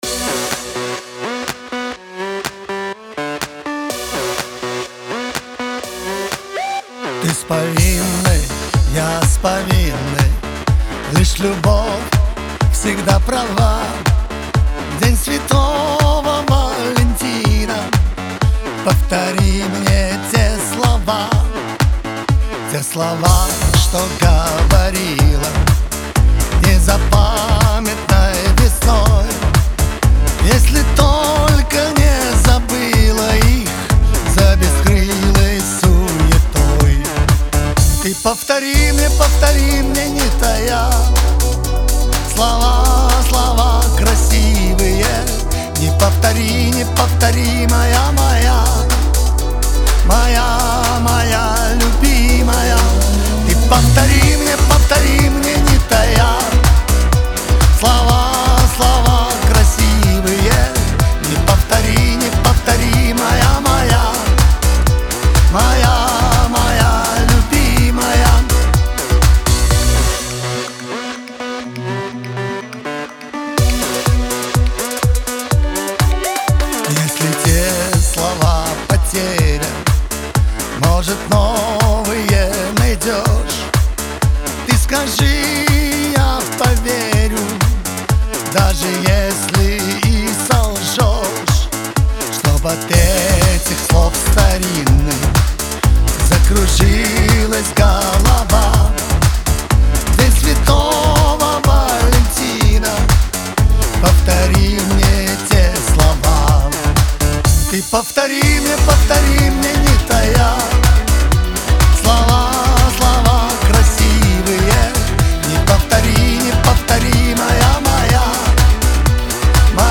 Лирика
диско
эстрада